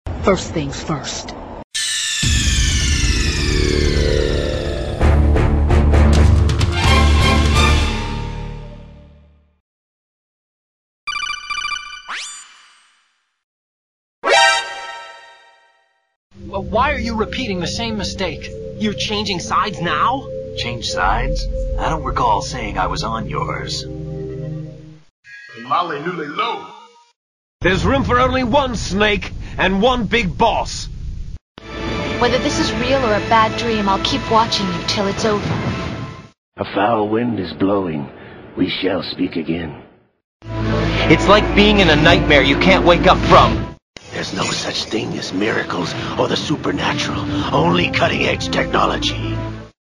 SAMPLES FOR THE METAL GEAR SOLID GAME!ALL THE SOUNDS AND FX GREAT !